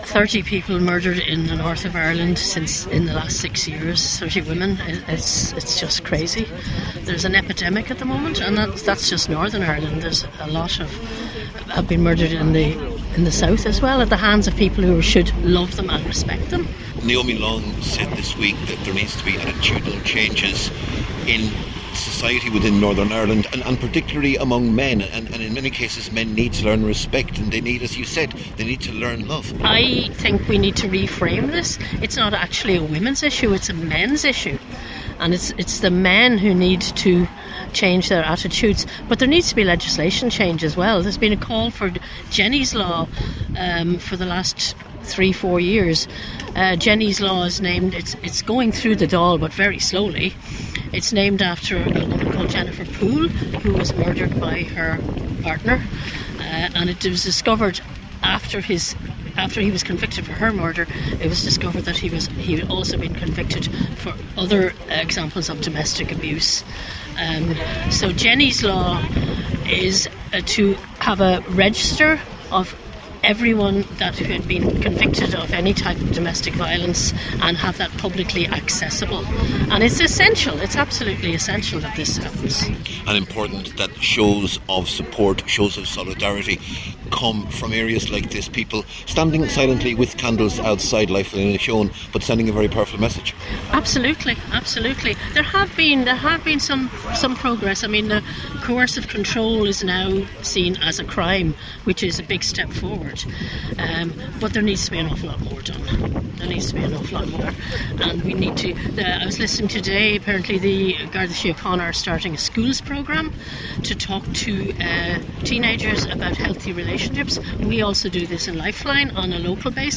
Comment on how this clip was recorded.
Over 150 people gathered outside the Lifeline Inishowen premises in Carndonagh last night for a candlelit vigil